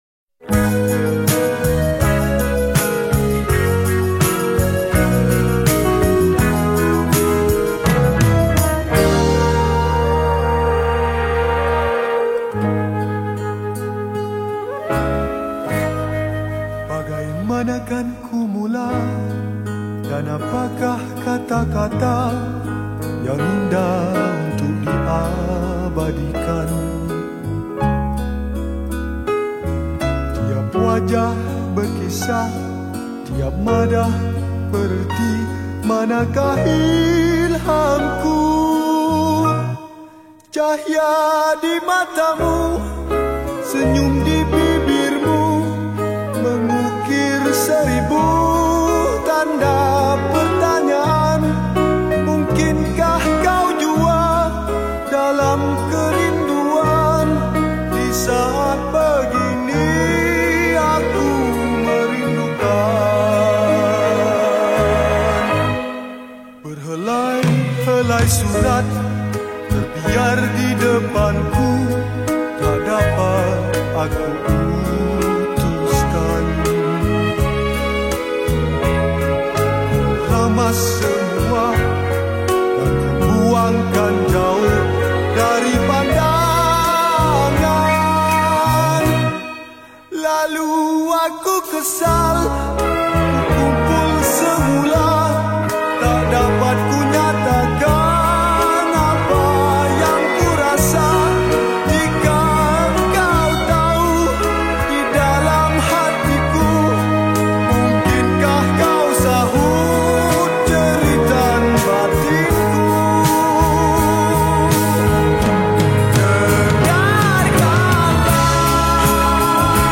Rock Kapak
Skor Angklung